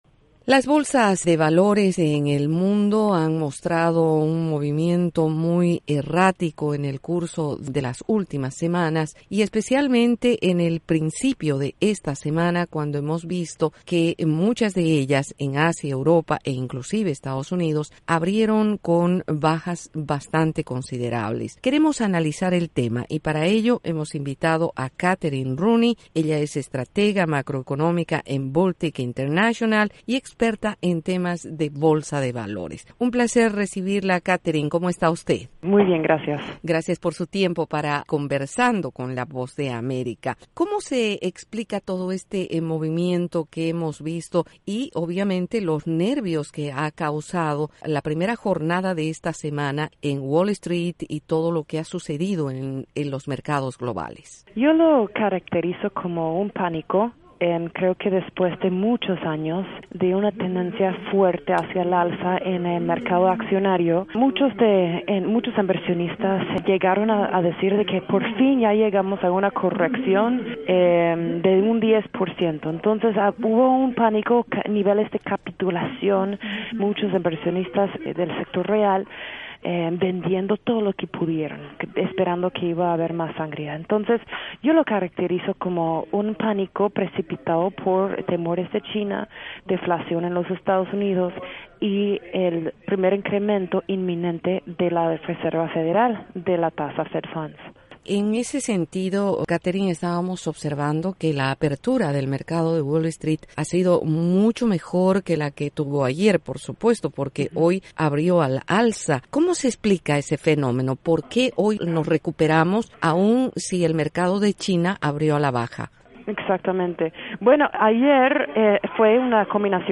Entrevista con la experta en mercados bursátiles